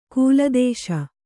♪ kūla dēśa